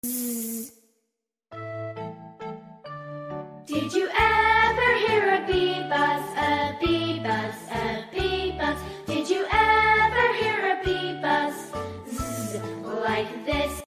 🐝 “Z z z!” says the buzzing sound effects free download
🐝 “Z-z-z!” says the buzzing bee! 🎶 Zooming through phonics with the letter Z — learning sounds has never been so fun!